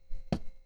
hitFabric2.wav